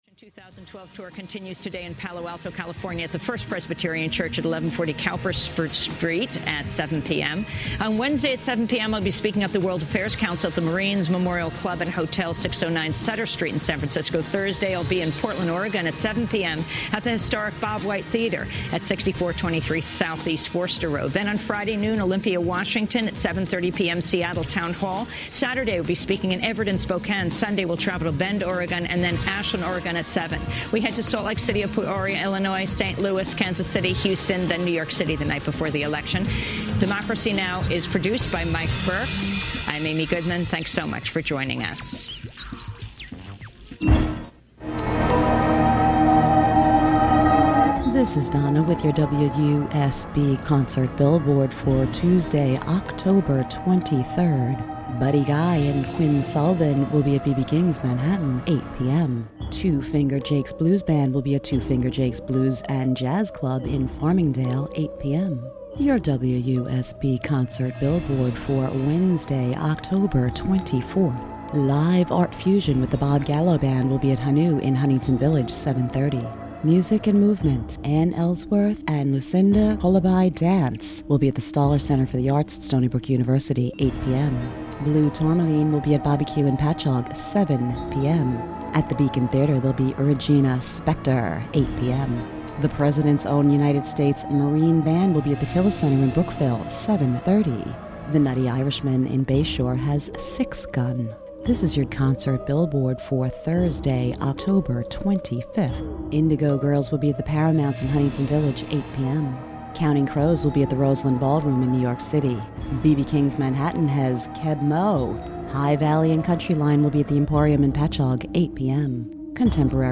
on the phone from Seattle where a long rain-free period continues